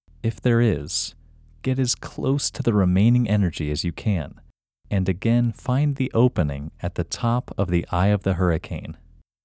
IN – Second Way – English Male 23